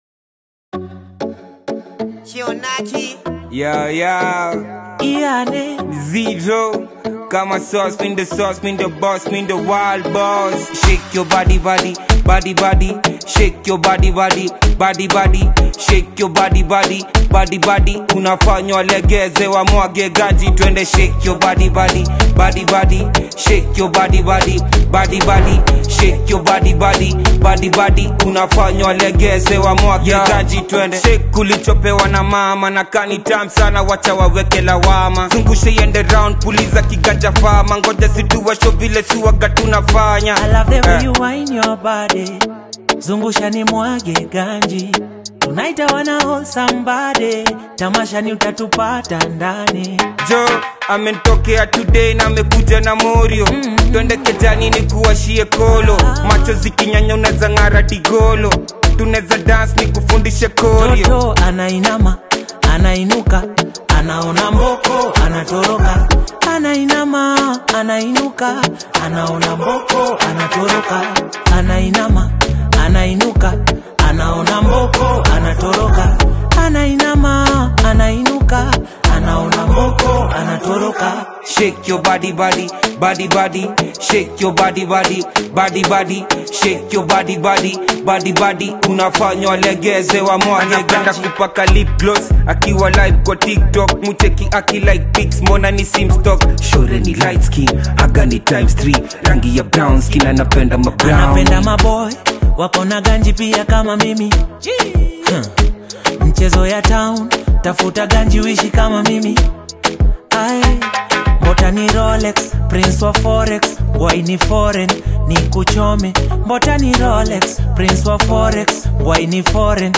confident and catchy rap verses
a smooth, melodic hook